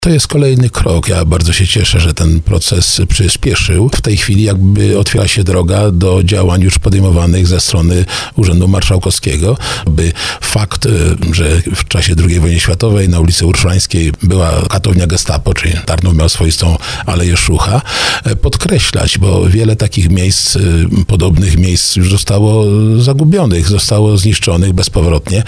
W rozmowie z RDN Małopolska zwracał uwagę, że jest to miejsce o wyjątkowym znaczeniu historycznym, a przekształcenie go w nowoczesne muzeum pozwoli młodemu pokoleniu zrozumieć skalę tragedii, jaka miała miejsce w Tarnowie, i uświadomi, że to miasto również miało swoją ‘aleję Szucha’.